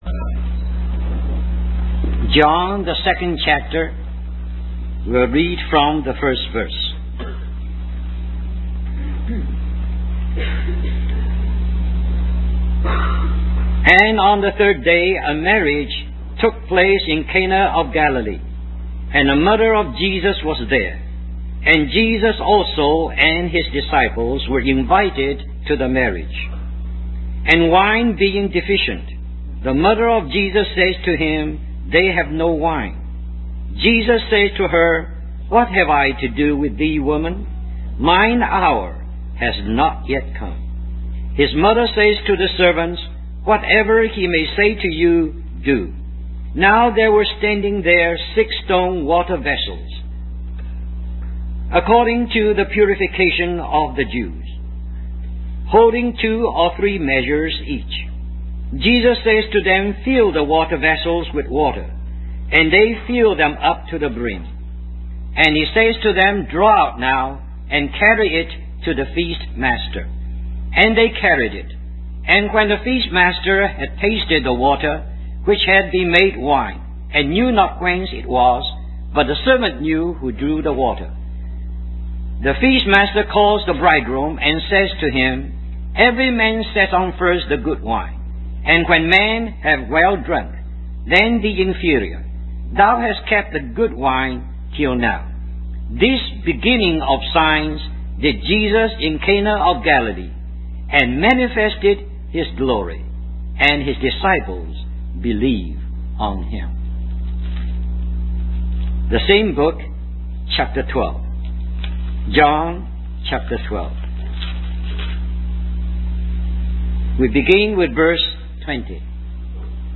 In this sermon, the preacher discusses the ultimate purpose of Jesus' life and movement.